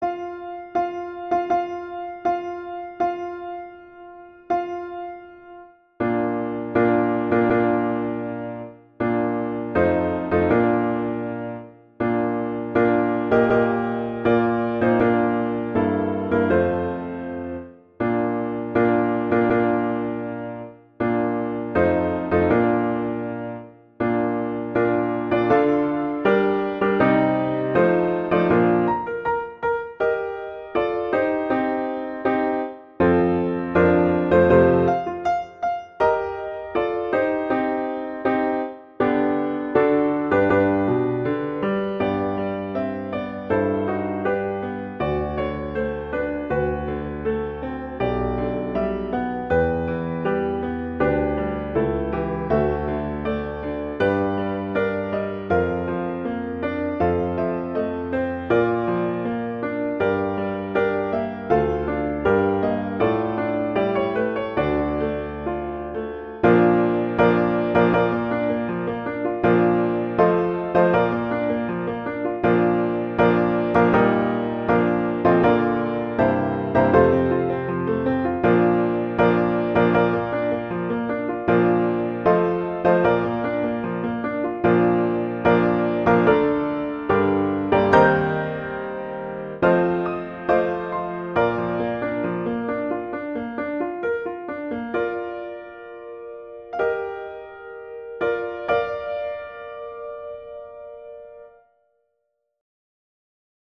classical, wedding, traditional, festival, love
Bb major
♩=80 BPM